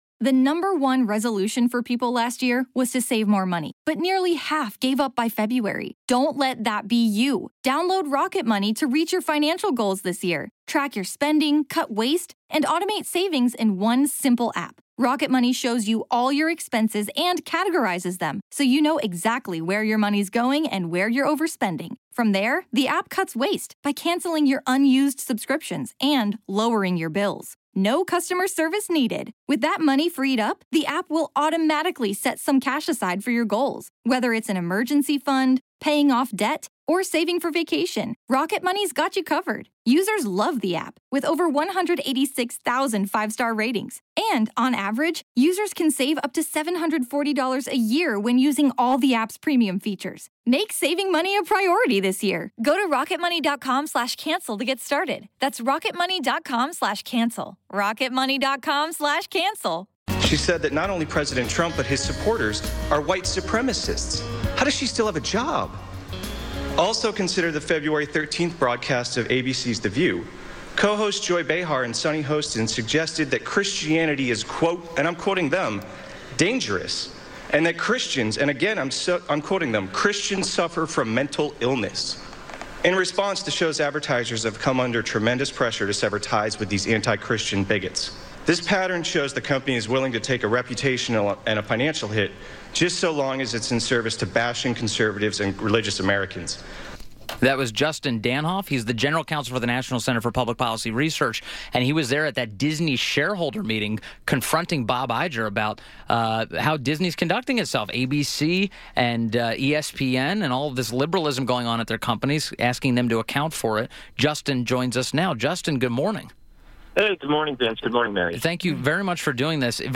Mornings on the Mall / WMAL Interview